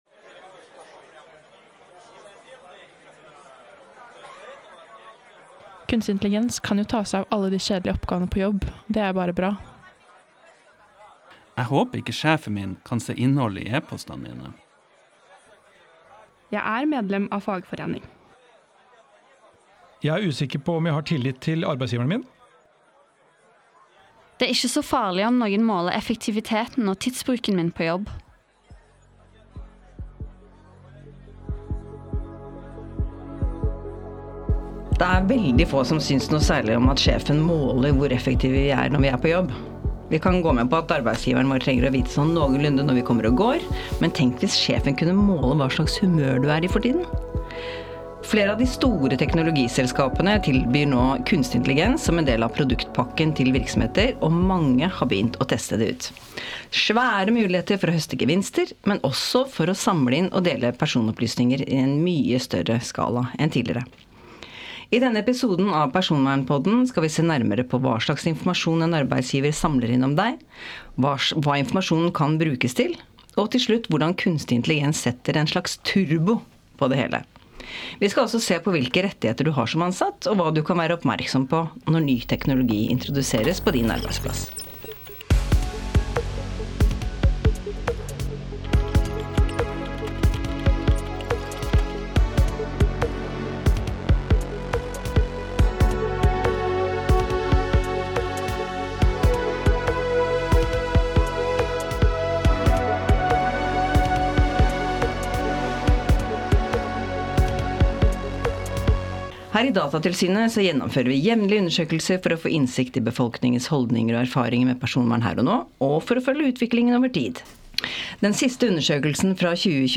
i studio